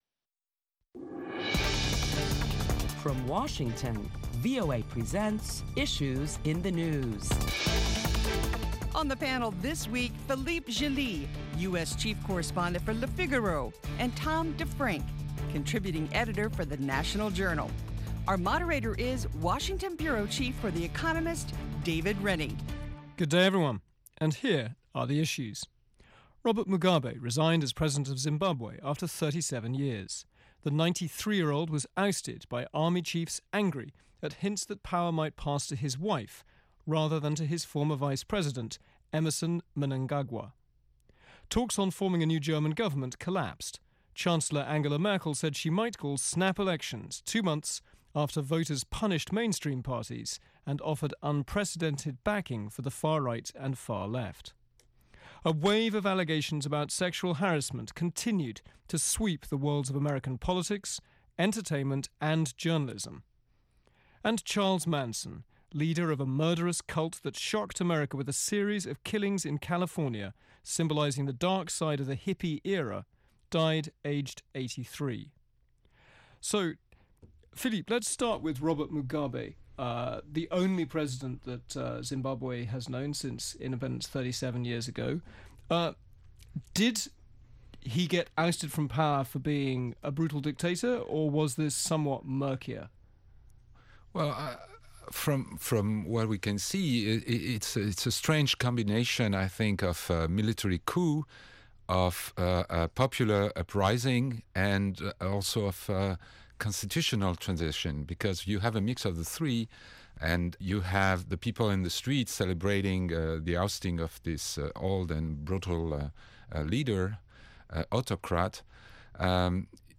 This week on Issues in the News, prominent Washington correspondents discuss topics making headlines around the world, including the resignation of longtime Zimbabwe leader, Robert Mugabe.